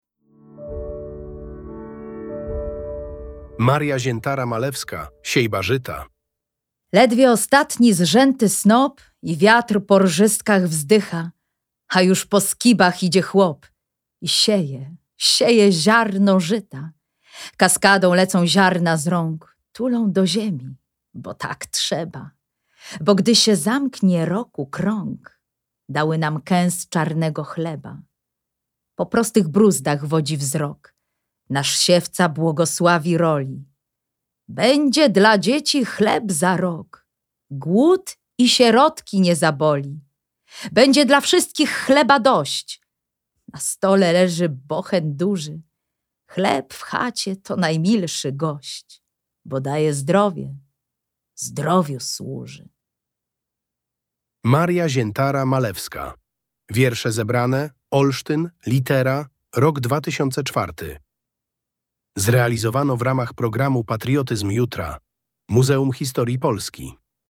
Nagranie wiersza warmińskiej poetki